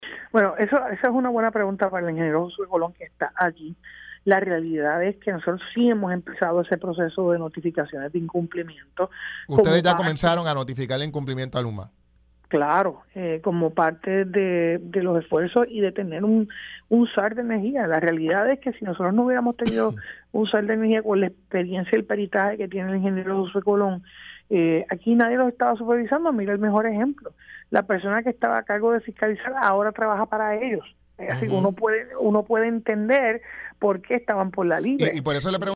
Sus expresiones se dieron durante el programa Pega’os en la Mañana, cuando se le preguntó si hay documentación que ejemplifique dicho incumplimiento bajo la administración de Fermín Fontanés como director de la Autoridad para las Alianzas Público Privadas (AAPP)
114-JENNIFFER-GONZALEZ-GOBERNADORA-COMIENZA-EL-ENVIO-DE-NOTIFICACIONES-DE-INCUMPLIMIENTO-A-LUMA-ENERGY.mp3